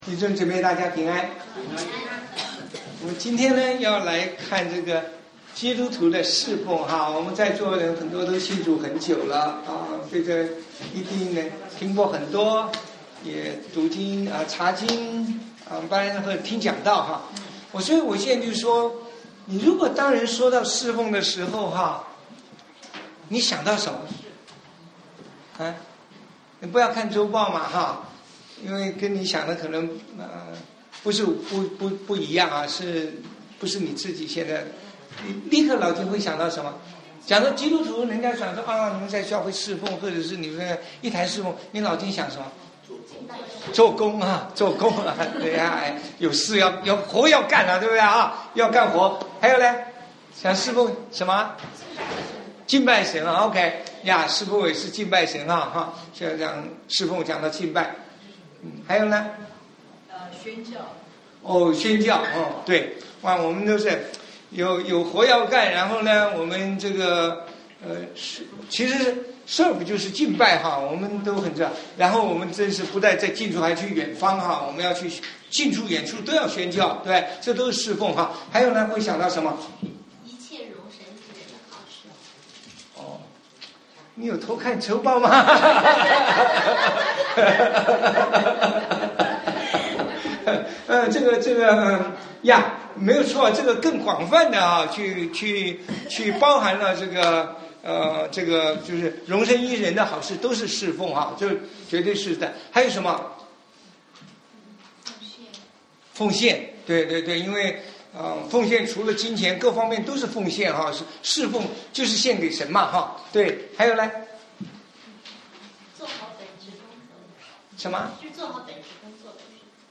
Play Audio 聽講道錄音 基督徒的事奉 羅馬書 12:1-16 引言：當人說到事奉、你想到甚麼？